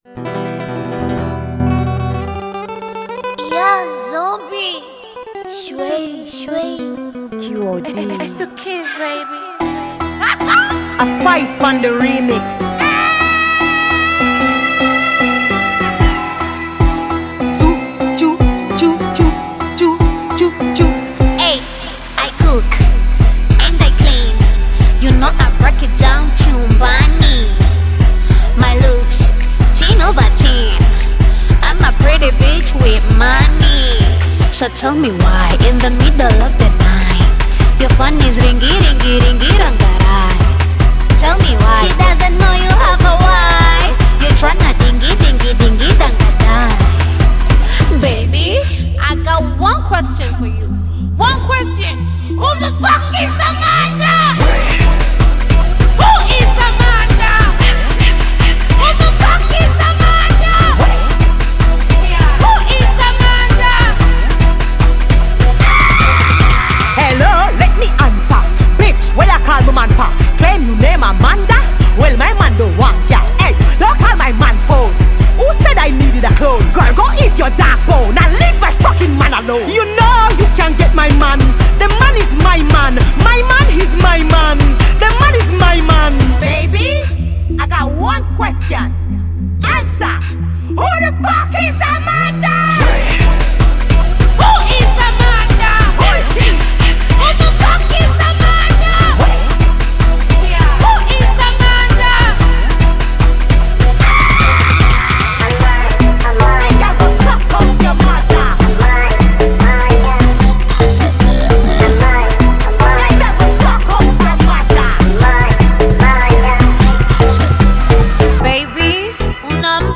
The tune features female rapper